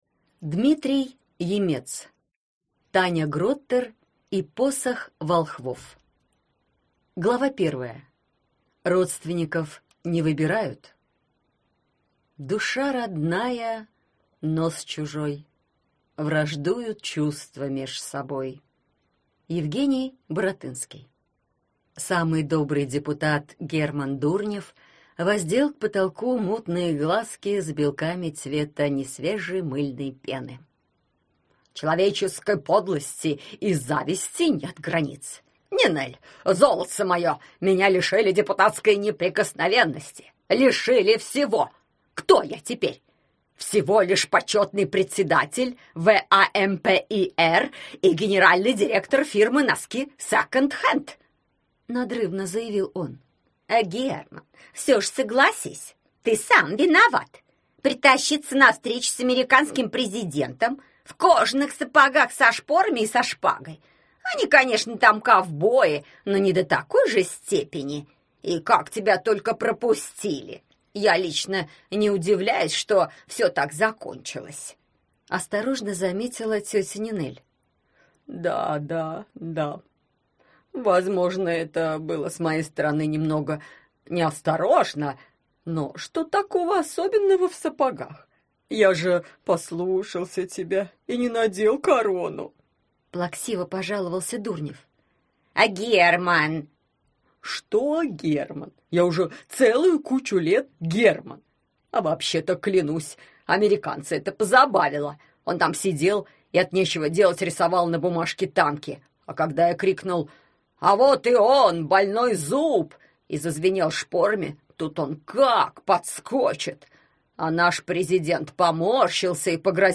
Аудиокнига Таня Гроттер и посох волхвов | Библиотека аудиокниг